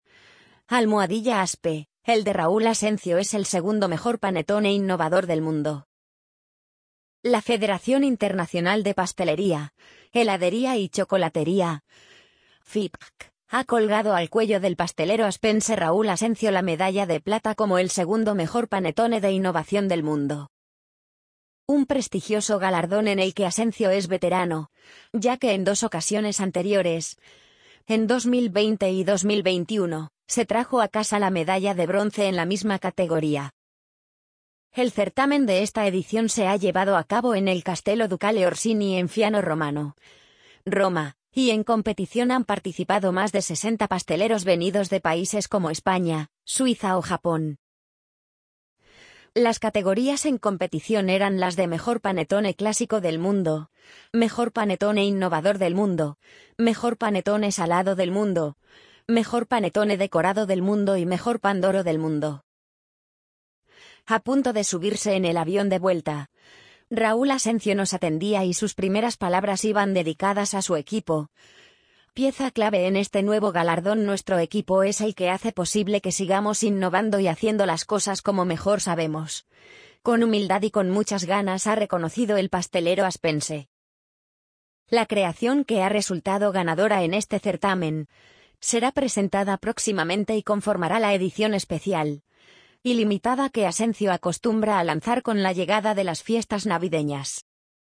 amazon_polly_61187.mp3